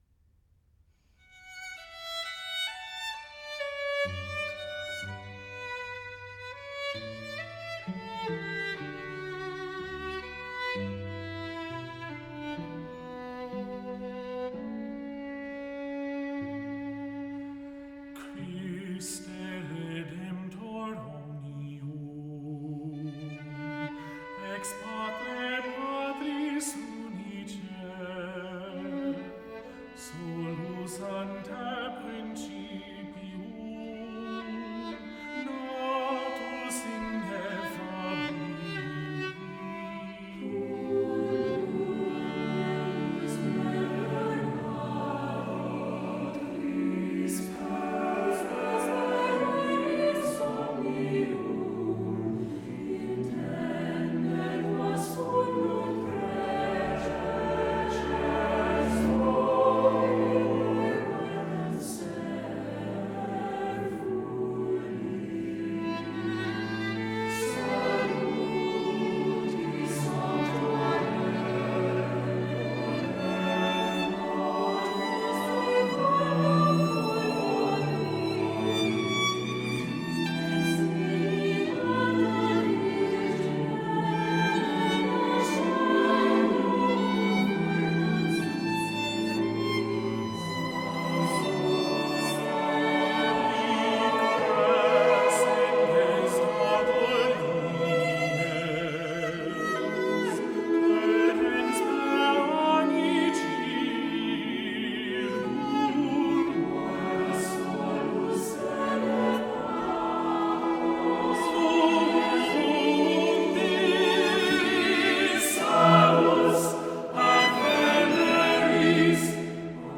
Voicing: "SSATBB with Tenor Solo, Violin, and Cello"